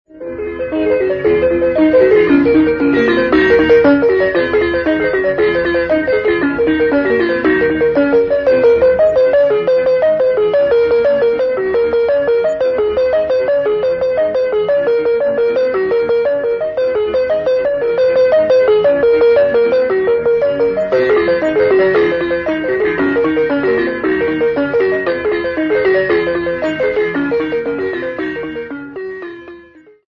Audio of Demonstration of left and right-handed melodies of the 'ground' on which the composition 'Lawanani michangasika timbila tamakono' is based